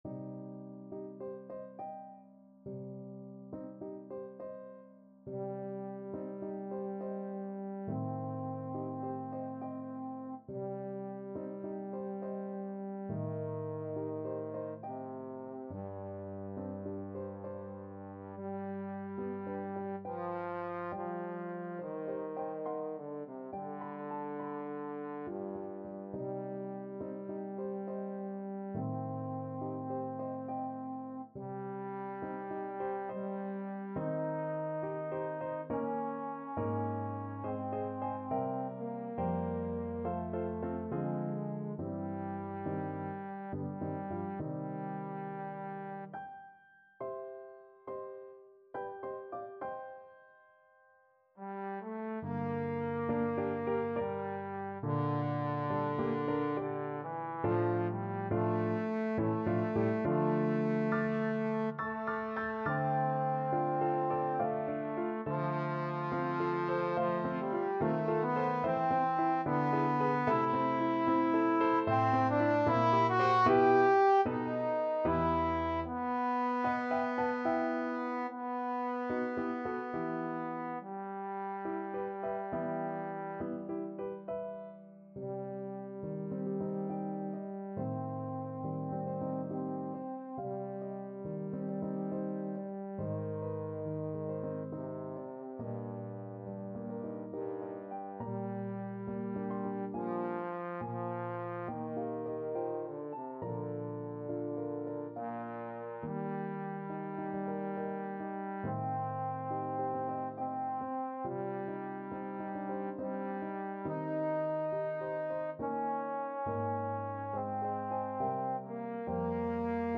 Classical Strauss, Richard Horn Concerto No.1, Op.11, 2nd Movement (Andante) Trombone version
Trombone
G3-G5
C minor (Sounding Pitch) (View more C minor Music for Trombone )
3/8 (View more 3/8 Music)
Andante =69
Classical (View more Classical Trombone Music)
strauss_horn_con1_op11_andante_TBNE.mp3